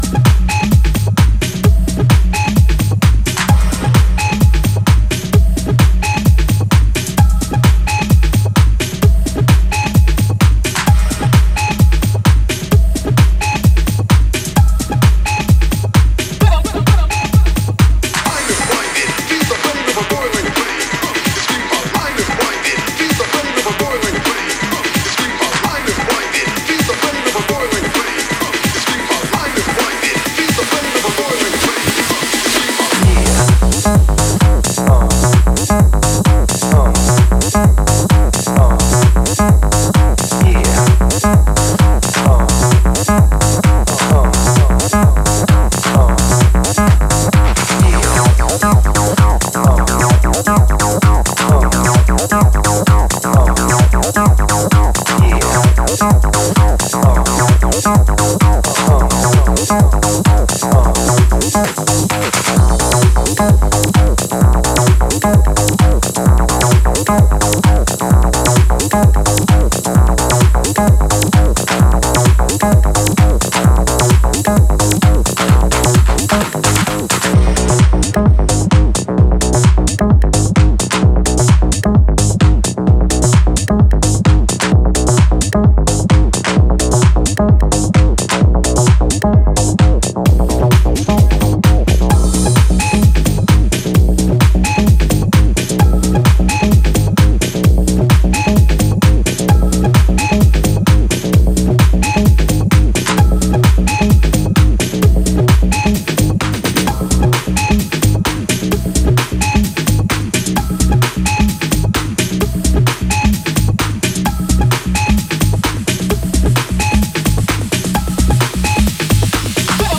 driving, analog acid squelch